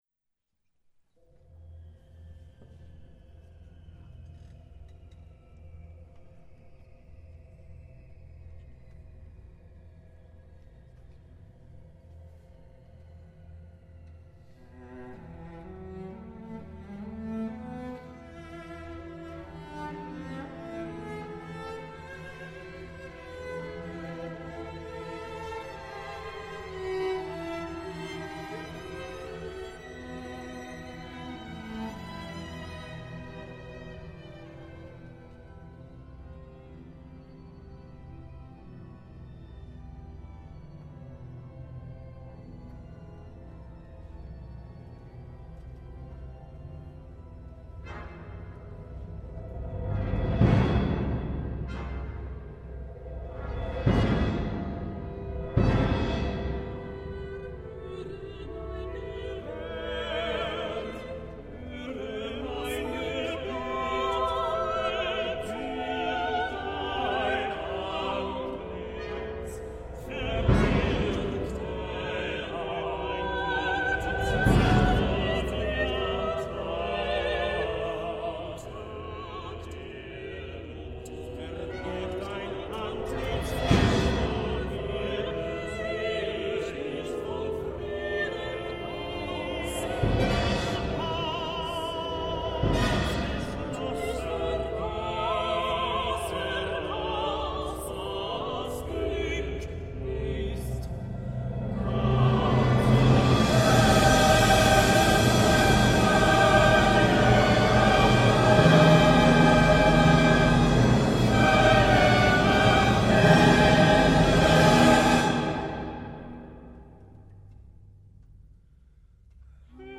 Stadtkirche St. Peter und Paul, Weimar
Sopran
Tenor
Bass